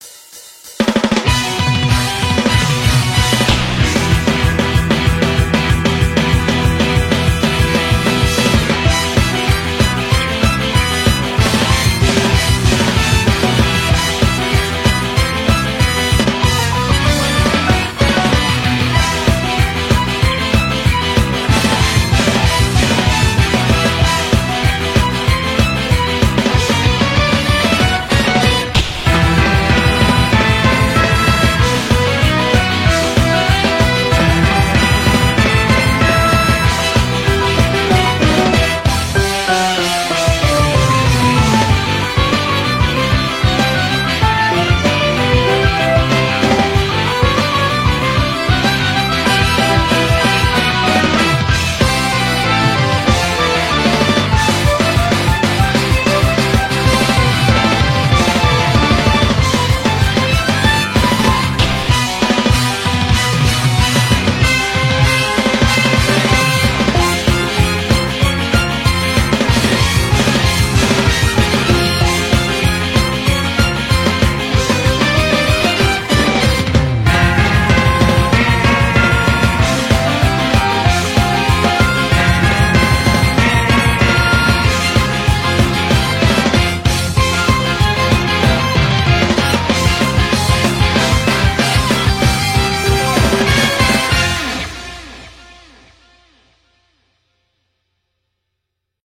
BPM190